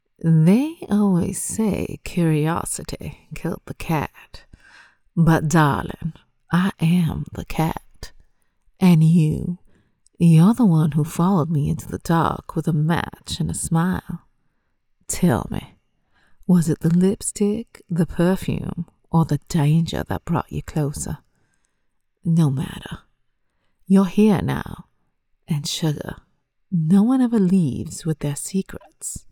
Character Samples
Southern-Seductress.mp3